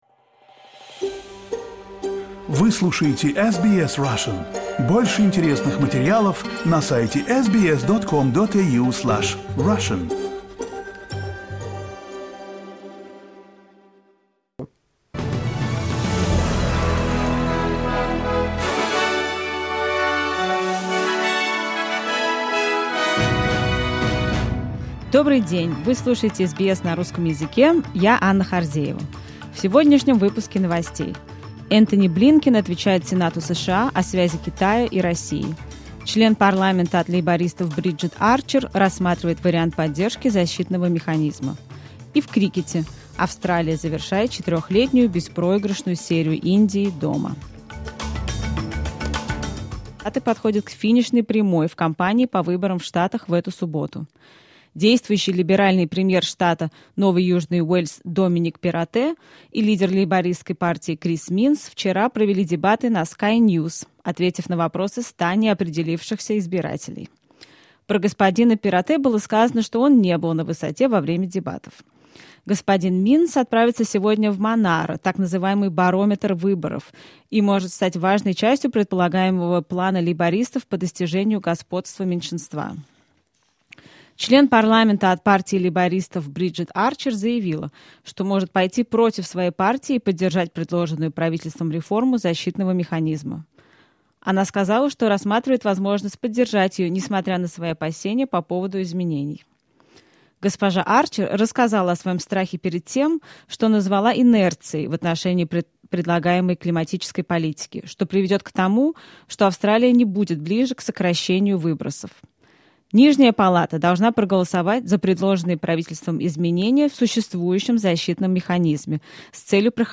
SBS news in Russian — 23.03.2023